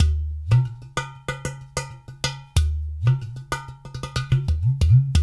93 -UDU 09R.wav